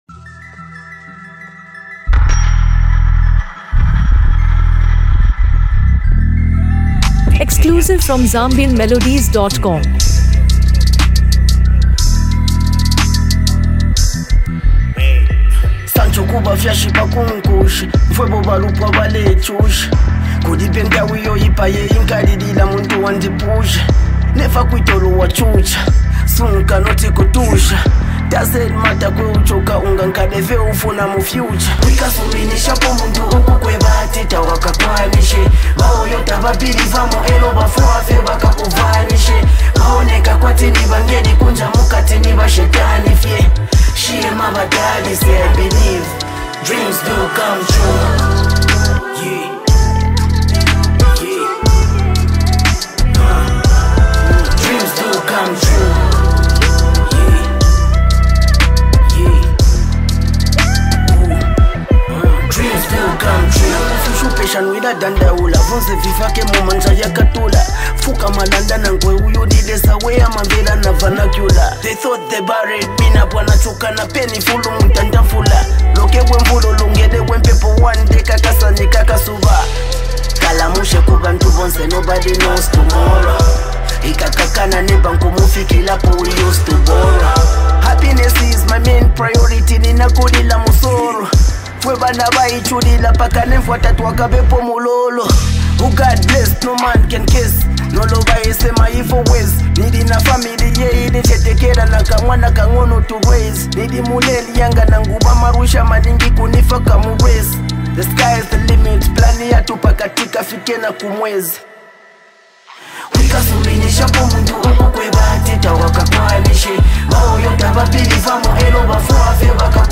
A Powerful Hip-Hop Anthem of Hope and Hustle
motivational track